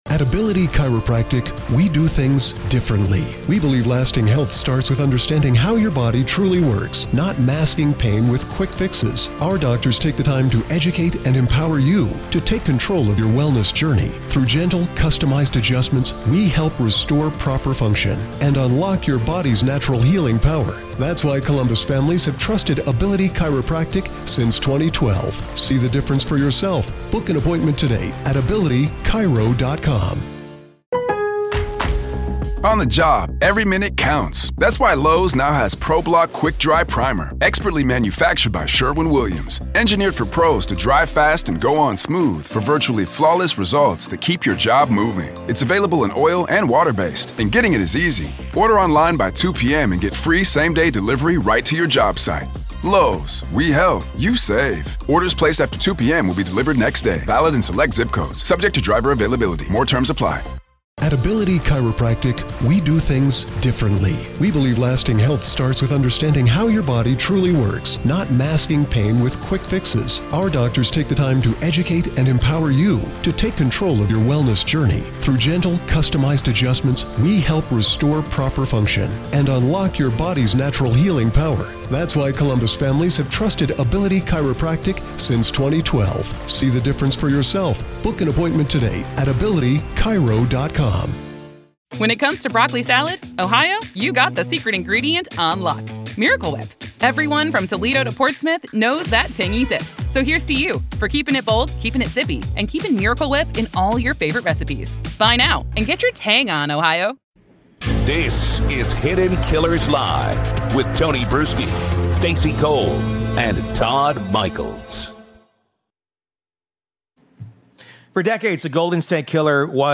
True Crime Today | Daily True Crime News & Interviews / The People vs. The Golden State Killer: How DA Thien Ho Finally Brought Joseph DeAngelo Down